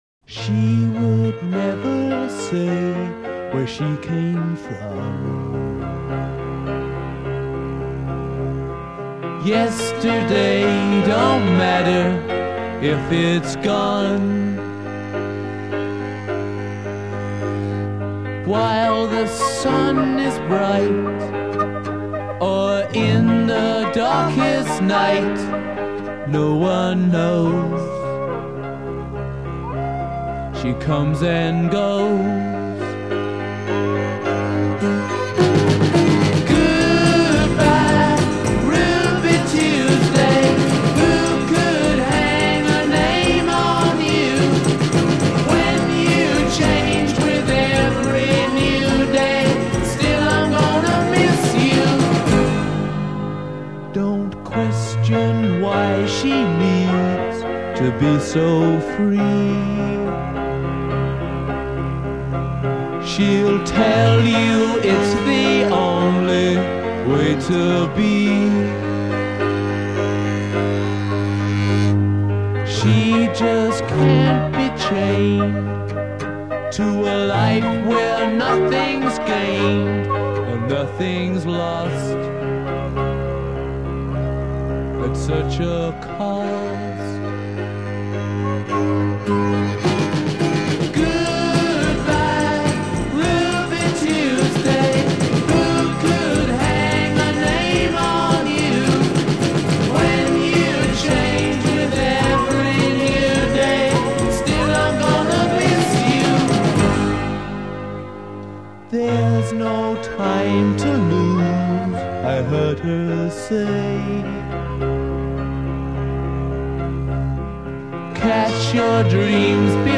coda : 4 recorder, string bass, piano, and guitar.